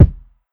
Break Kick.wav